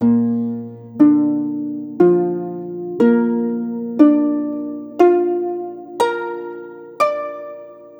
Cinematic 27 Harp 01.wav